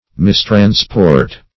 mistransport - definition of mistransport - synonyms, pronunciation, spelling from Free Dictionary
Search Result for " mistransport" : The Collaborative International Dictionary of English v.0.48: Mistransport \Mis`trans*port"\, v. t. To carry away or mislead wrongfully, as by passion.